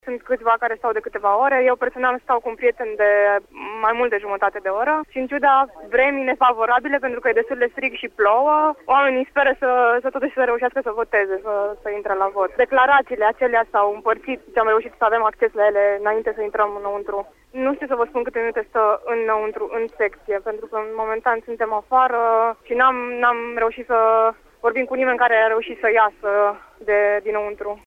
una dintre româncele care aşteaptă să voteze